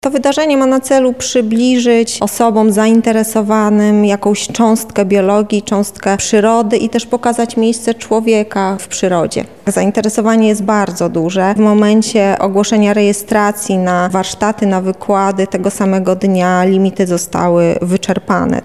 Noc-biologow-wywiad.mp3